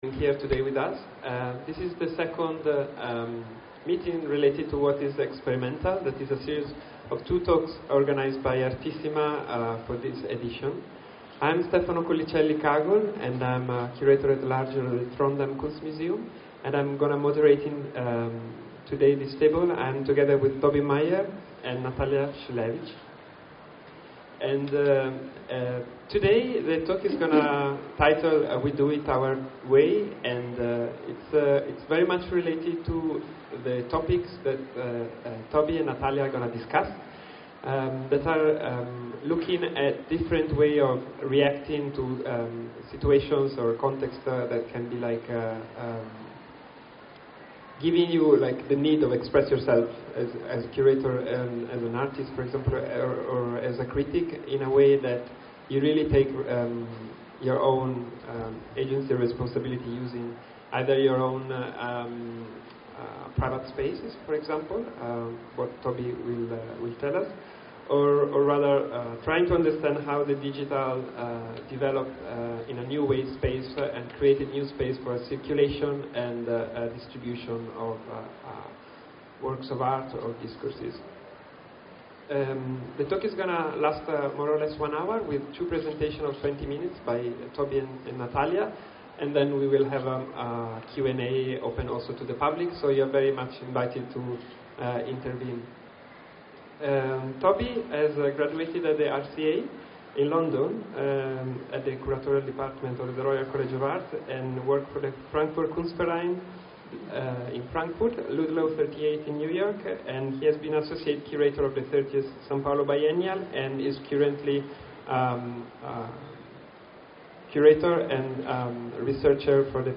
La redazione di KABUL magazine ha deciso di rendere disponibili, all’interno del sito, le registrazioni audio di alcuni dei talk di Artissima2016.